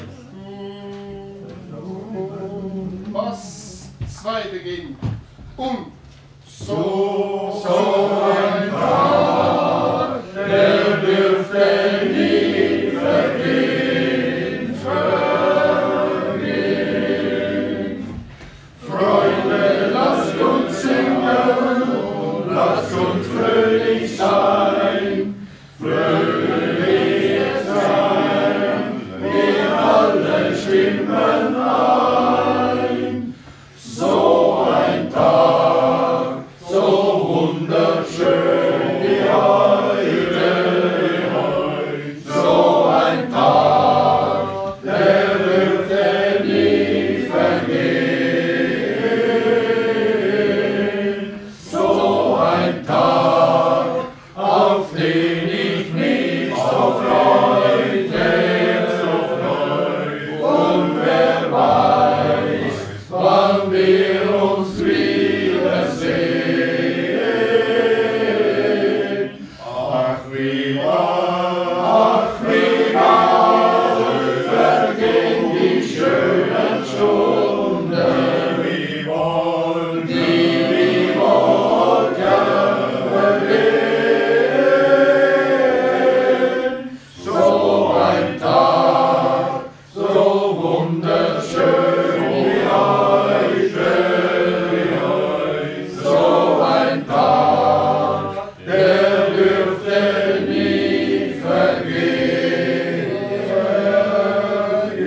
Generalversammlung 2025 – Männerchor Leibstadt